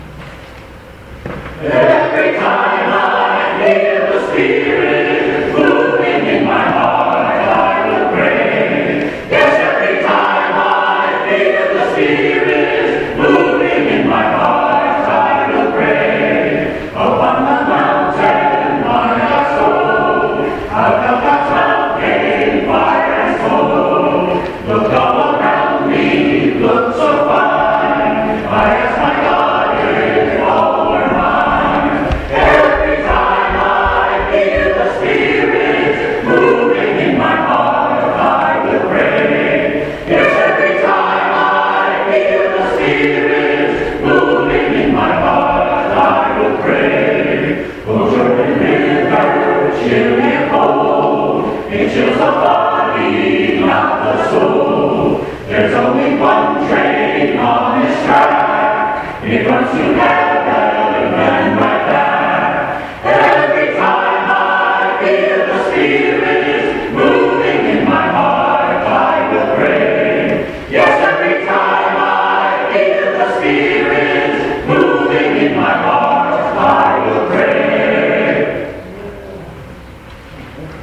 MCC Senior Choir with First Parish Sudbury Choir
Introit